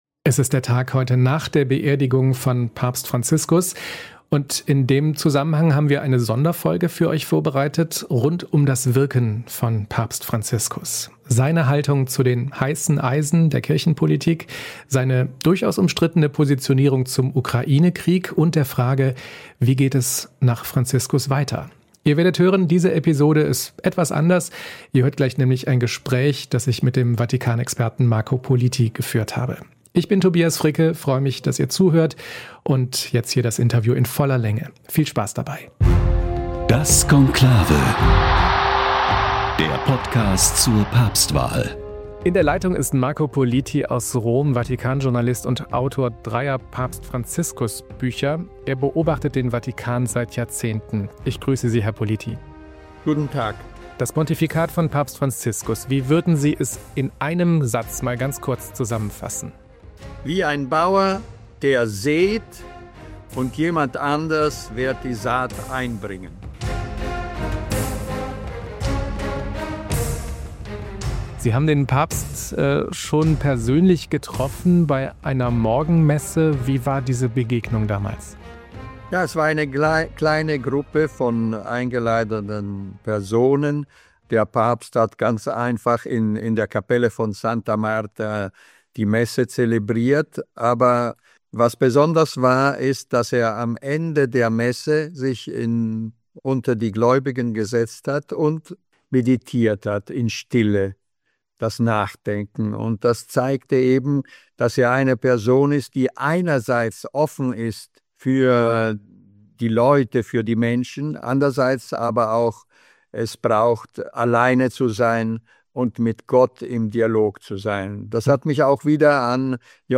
Bonus-Episode: Das Interview zum Wirken von Papst Franziskus ~ Das Konklave Podcast